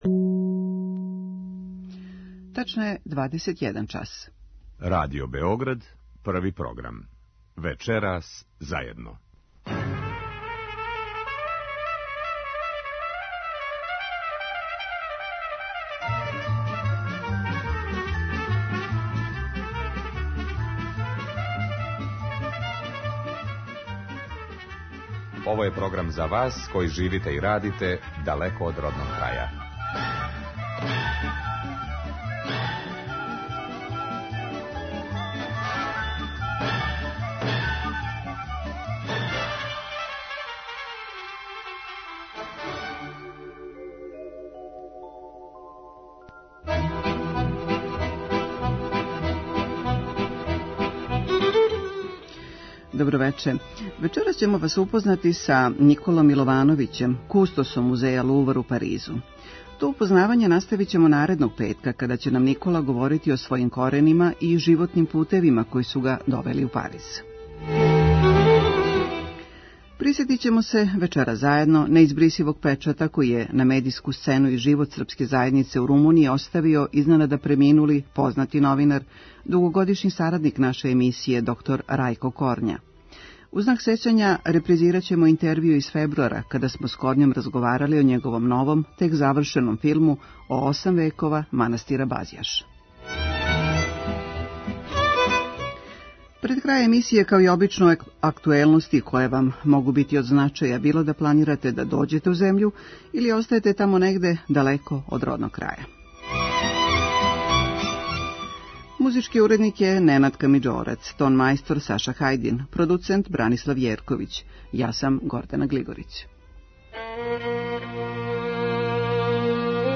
Емисија магазинског типа која се емитује сваког петка од 21 час.